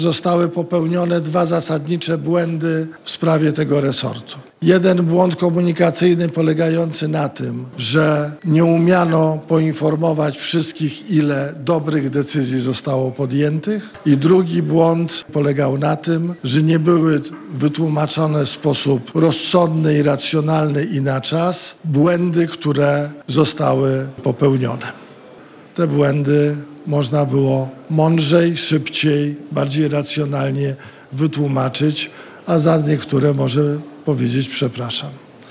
Włodzimierz Czarzasty, wiceprzewodniczący Nowej Lewicy mówił o błędach, które zostały popełnione w resorcie nauki